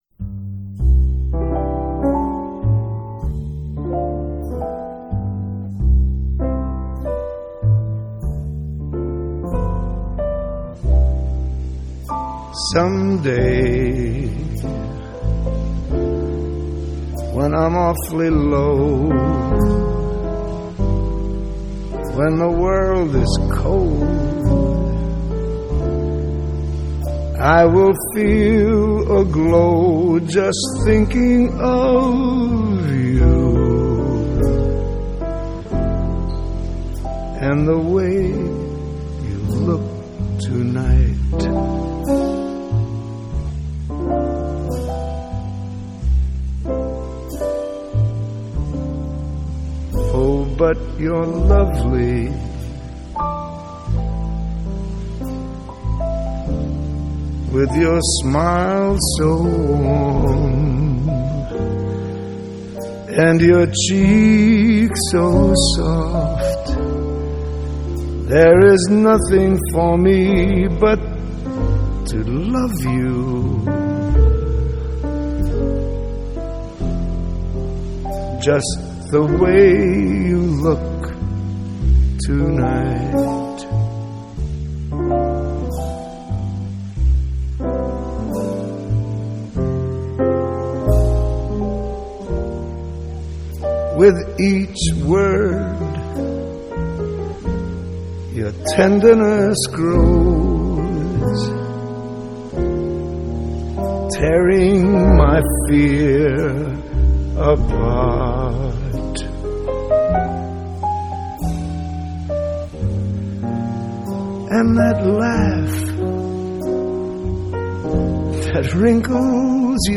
Jazz Vocal, Traditional Pop